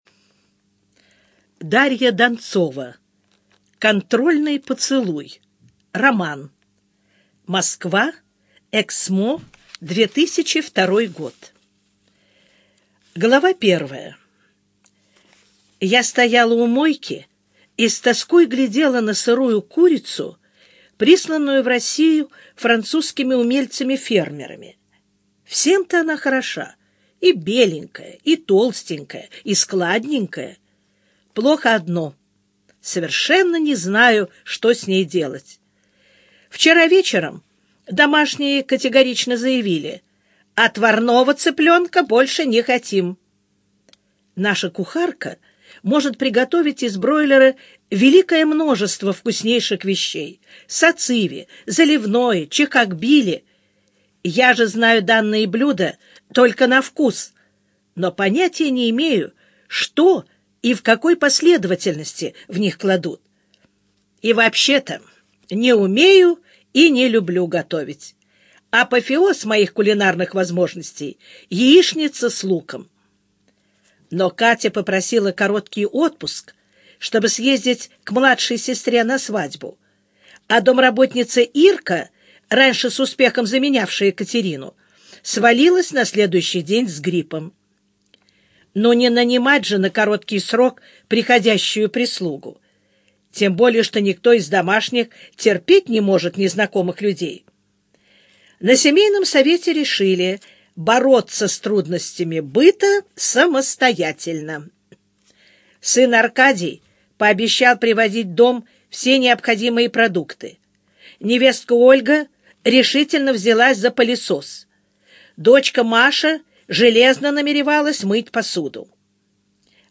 Аудиокнига Контрольный поцелуй - купить, скачать и слушать онлайн | КнигоПоиск